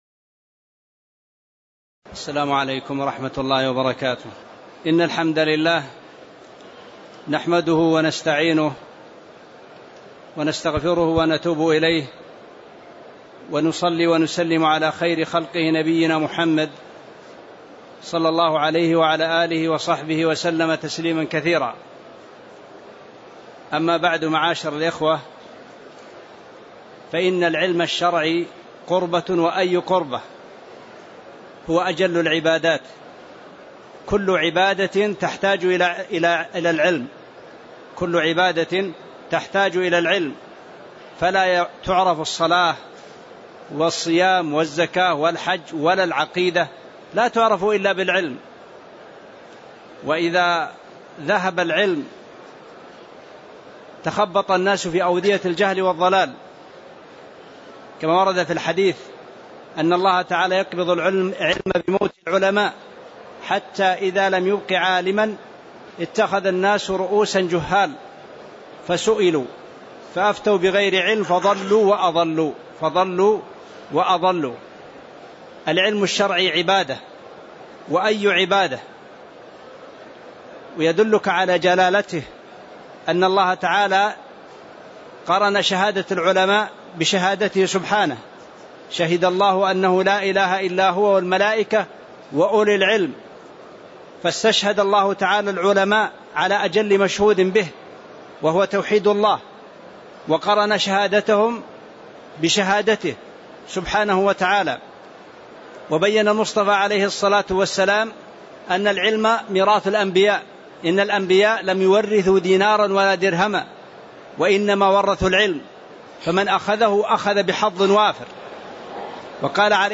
تاريخ النشر ٢٥ ذو الحجة ١٤٣٧ هـ المكان: المسجد النبوي الشيخ